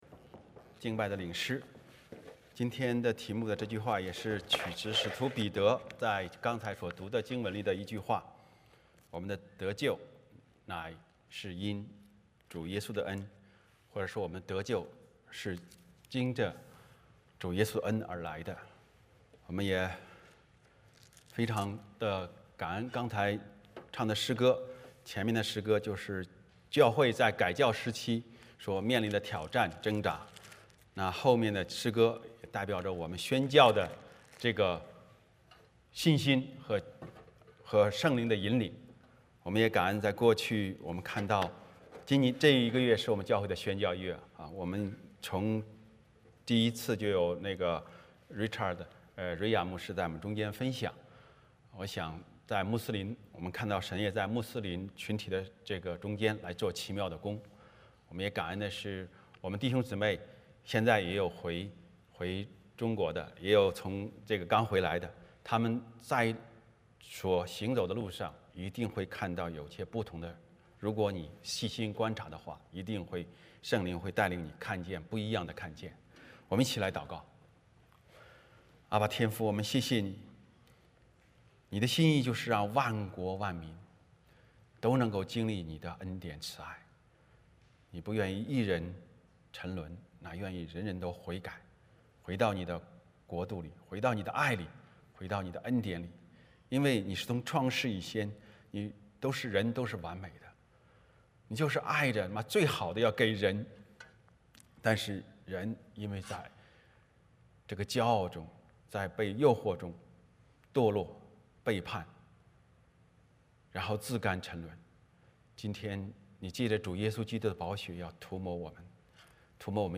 Passage: 使徒行传 15:3-21 Service Type: 主日崇拜 欢迎大家加入我们的敬拜。